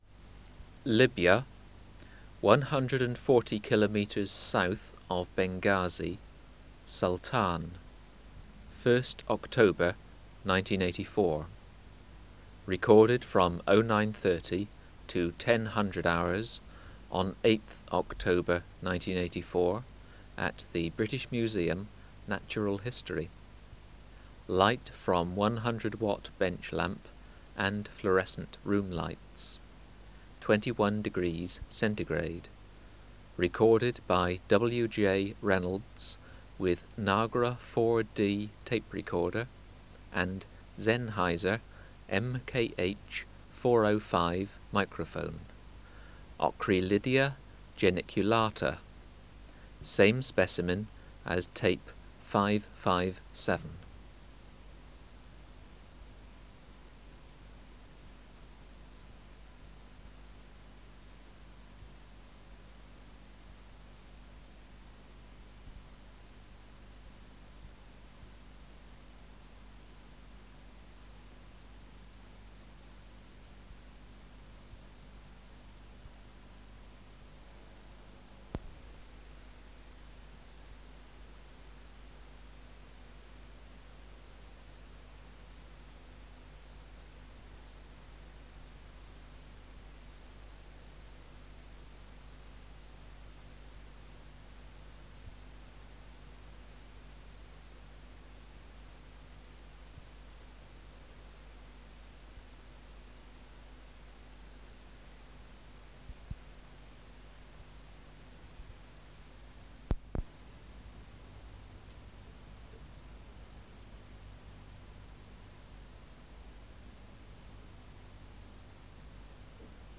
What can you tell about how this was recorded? Recording Location: BMNH Acoustic Laboratory Substrate/Cage: small recording cage 1 male and 2 females in glass jar 60 cm behind microphone. Microphone & Power Supply: Sennheiser MKH 405 Distance from Subject (cm): 10 Filter: Low Pass, 24 dB per octave, corner frequency 20 kHz